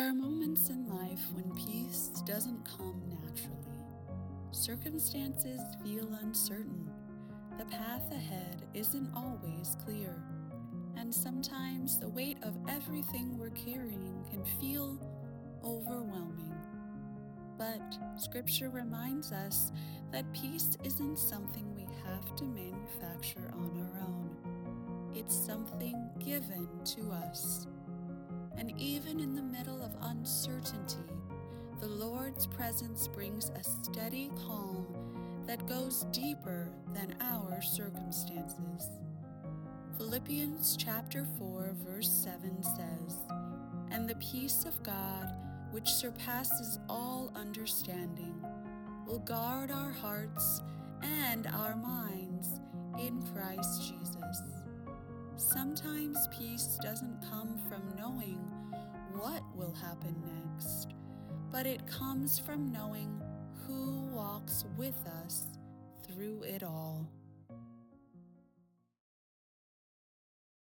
Devotional Narration
English - Midwestern U.S. English
Operate a broadcast-quality home studio featuring a Shure SM7B microphone and Universal Audio Apollo Twin interface, delivering clean WAV audio with quick turnaround and professional editing.
My voice has a calm, reassuring quality that helps listeners feel both informed and encouraged.
Peace Devotional.wav